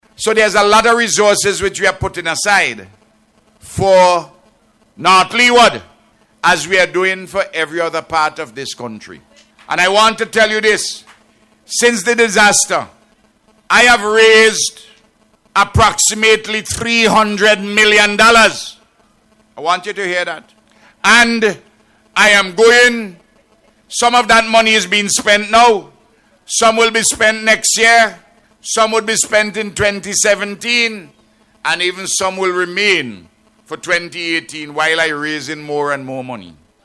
The Prime Minister made the point, as he delivered the feature address at a ceremony held in North Leeward yesterday to mark the start of construction on the Chateaubelair Bridge, which was damaged during the Christmas 2013 floods.